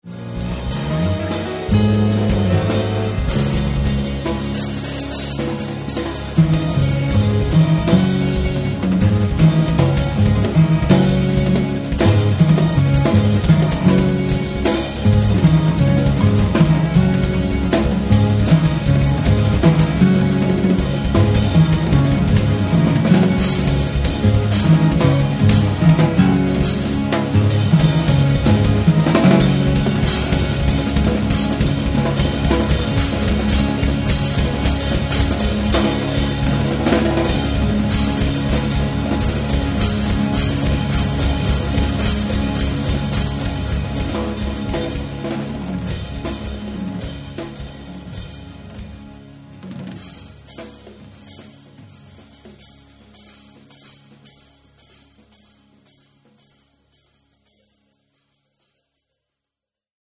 Registrato a Teramo nel Marzo 2003
Pianoforte
Basso elettrico e acustico
Batteria e tabla
progressive-jazz